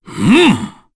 Dakaris-Vox_Attack4_kr.wav